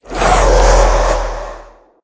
minecraft / sounds / mob / wither / idle4.ogg